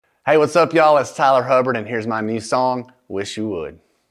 LINER Tyler Hubbard (Wish You Would) 1
LINER-Tyler-Hubbard-Wish-You-Would-1.mp3